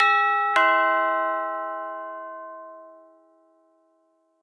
sounds_doorbell_02.ogg